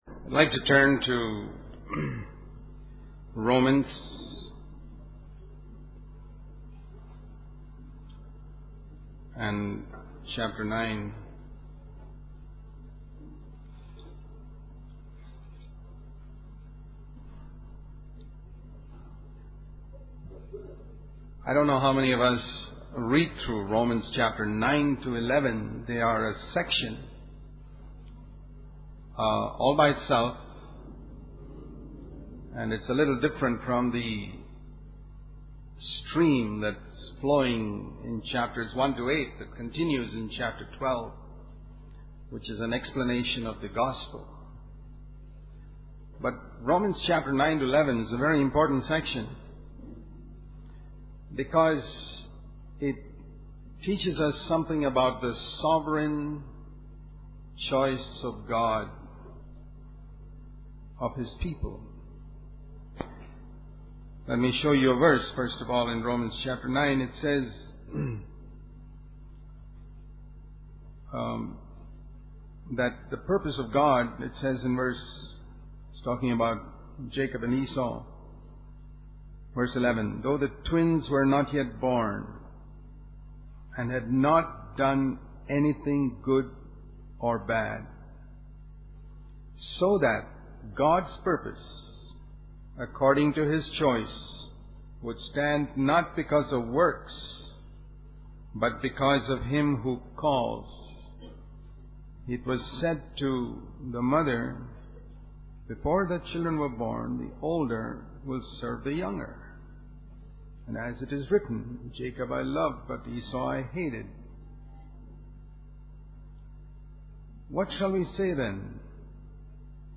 This sermon emphasizes the importance of being poor in spirit, recognizing that all blessings and abilities come from God. It warns against the dangers of spiritual pride and the need to constantly humble oneself before God, acknowledging that everything received is a gift from Him.